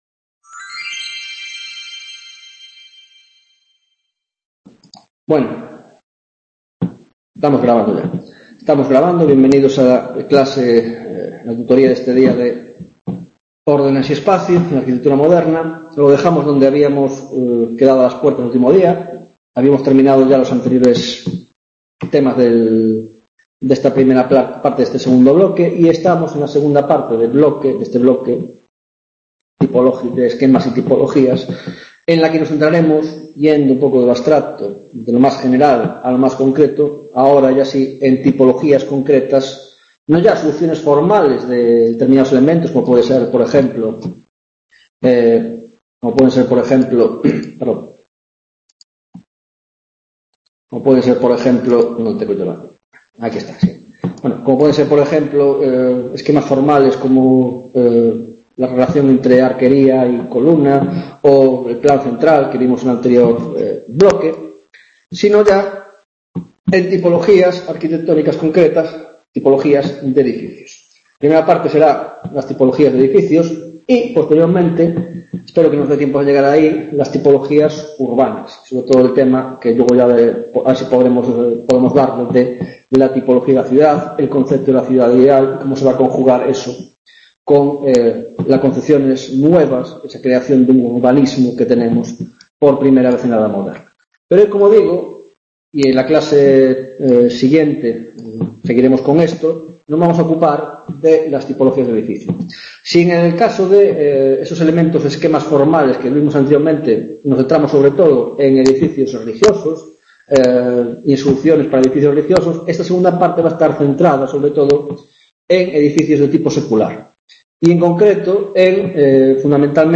9ª Tutoría de Órdenes y Espacio en la Arquitectura del XV - XVIII - Palacio 1, modelo italiano de Palacio